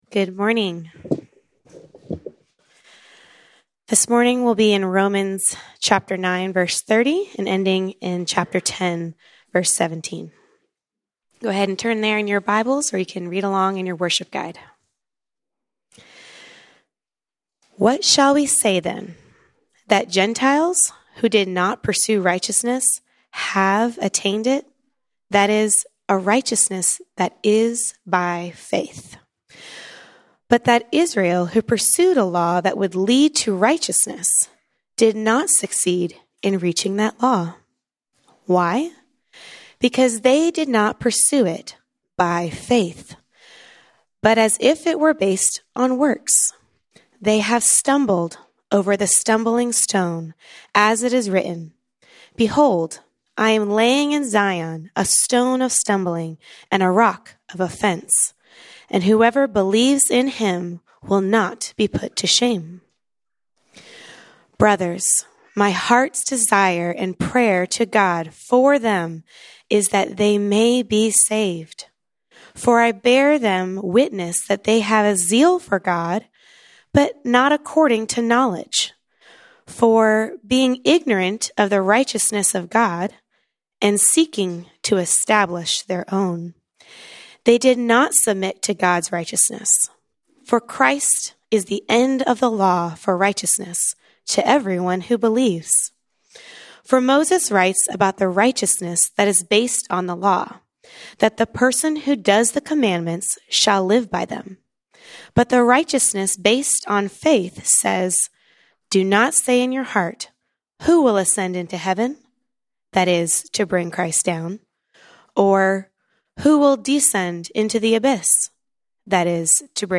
Format: Sermons